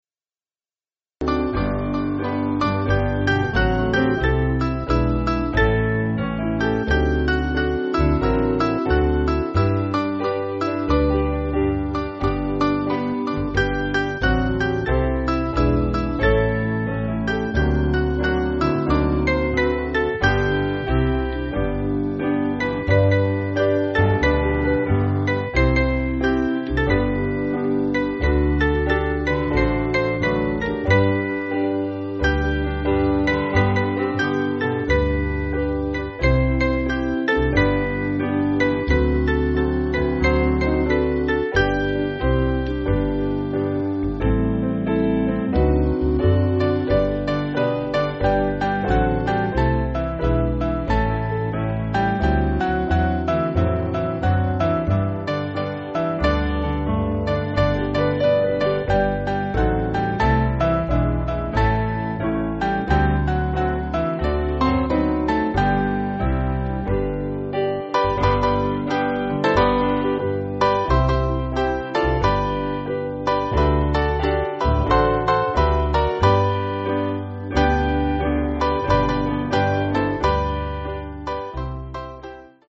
Mainly Piano
Lilt